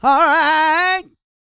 E-GOSPEL 124.wav